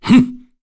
Dakaris-Vox_Attack1_kr.wav